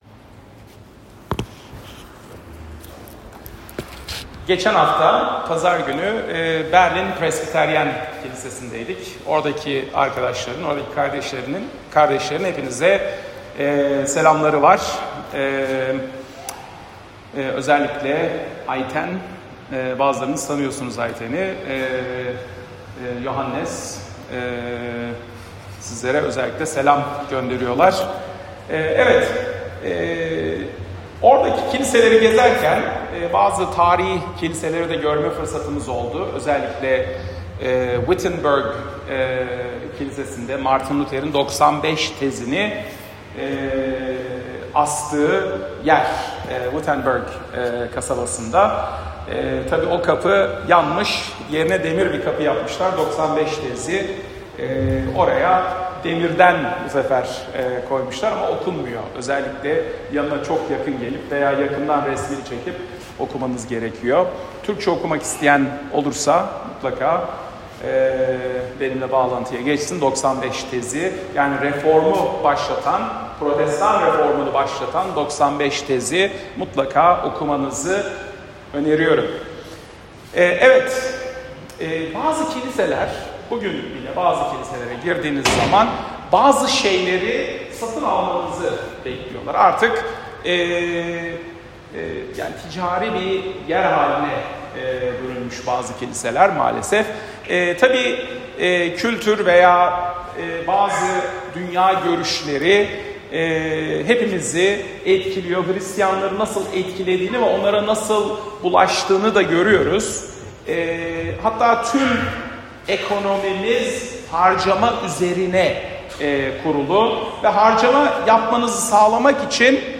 Salı, 1 Nisan 2025 | Romalılar Vaaz Serisi 2024-26, Vaazlar